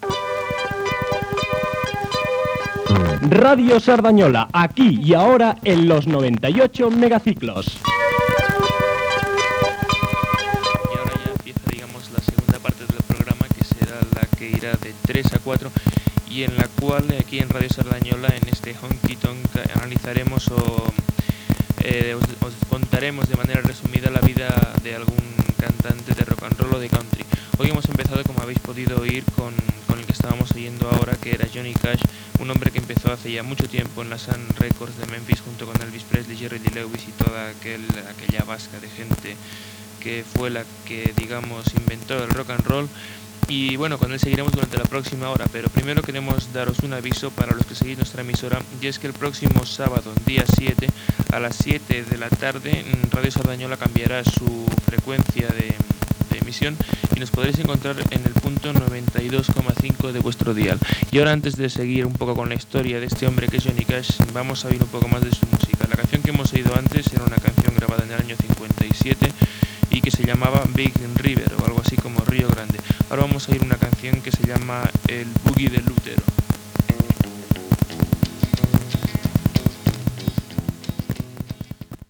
Avís del canvi de freqüència de l'emissora i tema musical.
Musical
Qualitat de l'àudio deficient